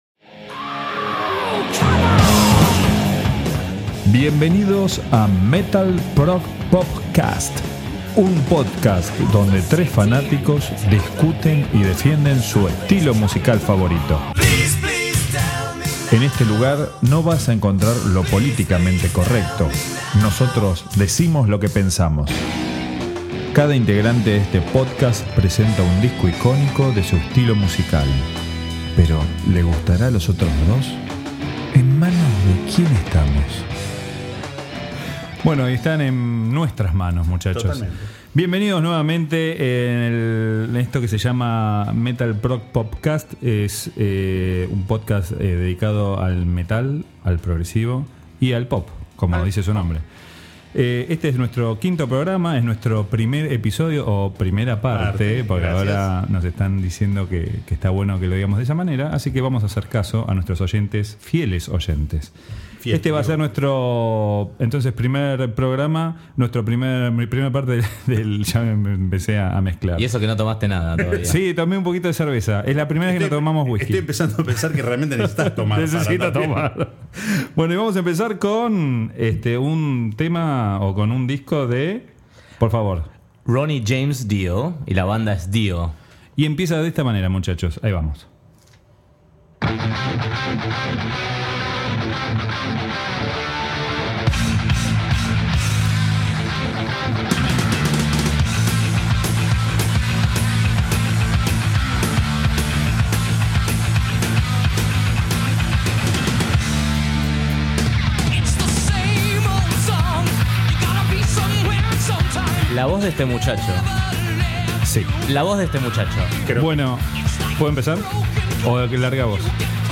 En MetalProgPop Cast nos juntamos 4 amigos para hablar y discutir sobre música.